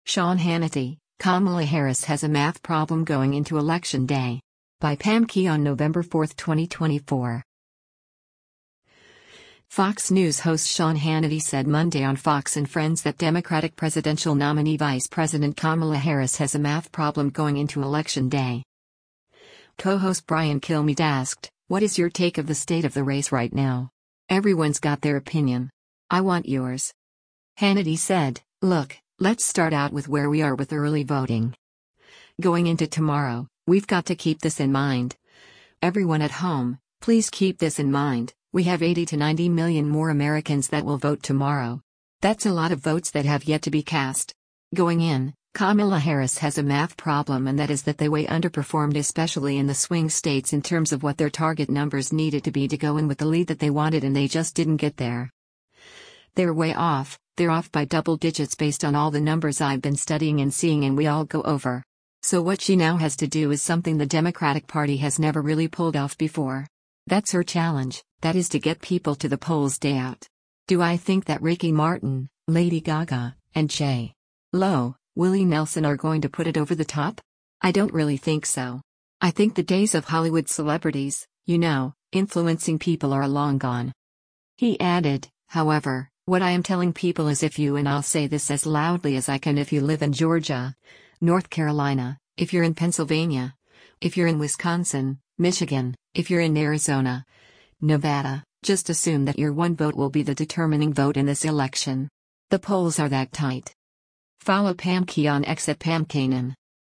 Fox News host Sean Hannity said Monday on “Fox & Friends” that Democratic presidential nominee Vice President Kamala Harris has a “math problem” going into Election Day.